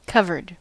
Ääntäminen
Ääntäminen US Tuntematon aksentti: IPA : /kʌvɜːd/ Haettu sana löytyi näillä lähdekielillä: englanti Käännös Adjektiivit 1. peitetty 2. katoksellinen Covered on sanan cover partisiipin perfekti.